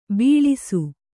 ♪ bīḷisu